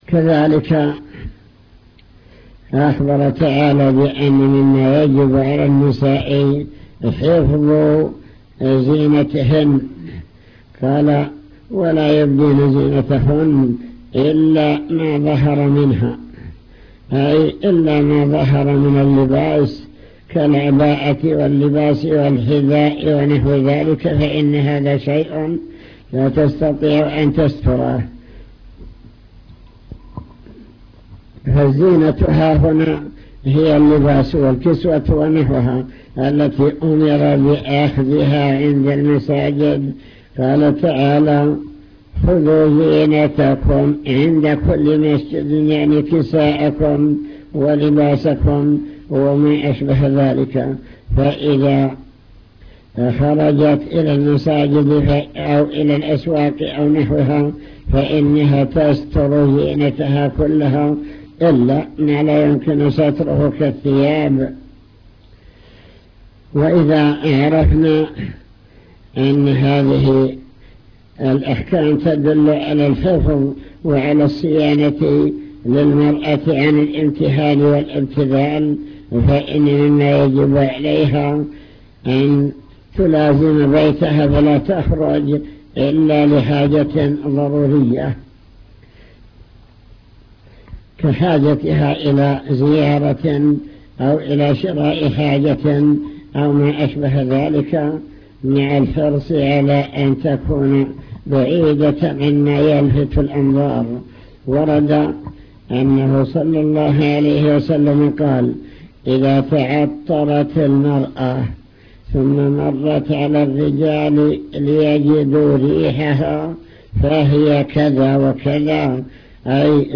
المكتبة الصوتية  تسجيلات - محاضرات ودروس  محاضرة بكلية البنات